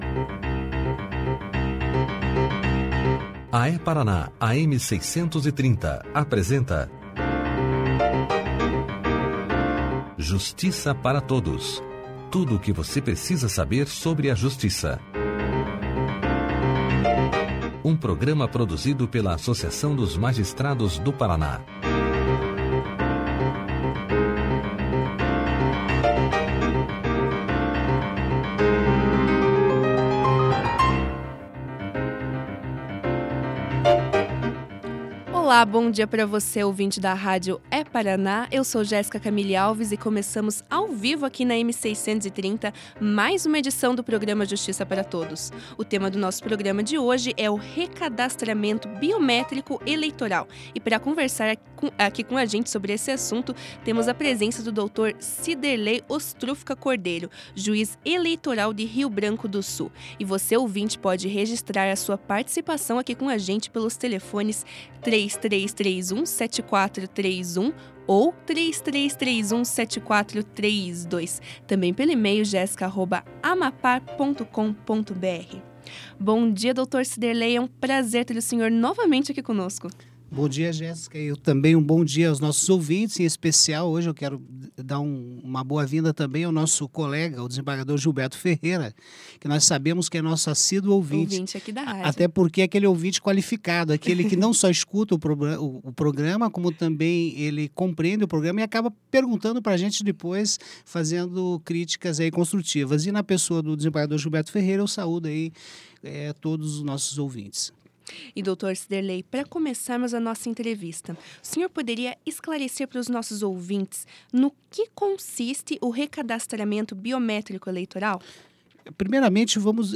Clique aqui e ouça a entrevista do Juiz Eleitoral de Rio Branco do Sul, Dr. Siderlei Ostrufka Cordeiro sobre recadastramento biométrico na íntegra.